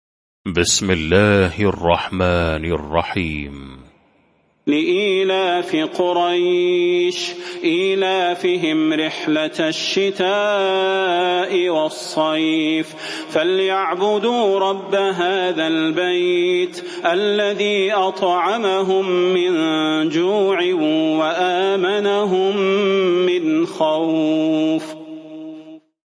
المكان: المسجد النبوي الشيخ: فضيلة الشيخ د. صلاح بن محمد البدير فضيلة الشيخ د. صلاح بن محمد البدير قريش